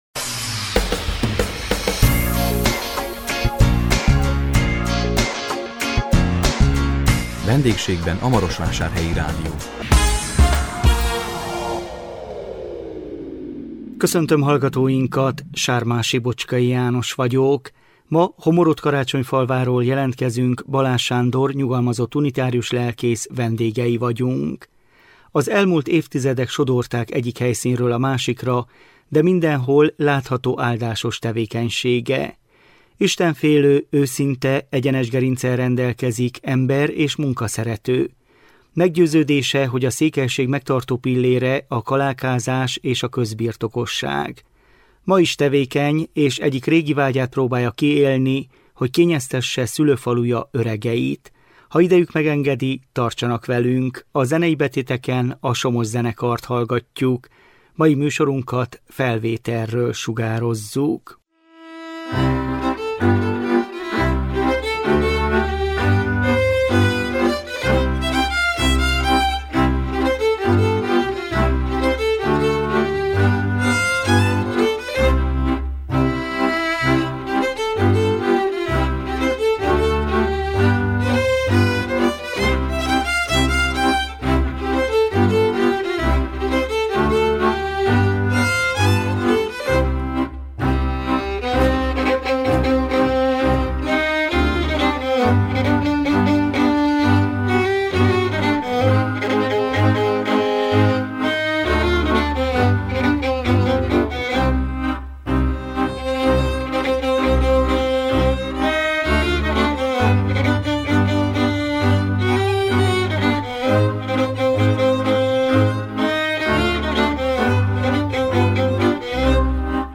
A 2024 december 5-én közvetített VENDÉGSÉGBEN A MAROSVÁSÁRHELYI RÁDIÓ című műsorunkkal Homoródkarácsonyfalváról jelentkeztünk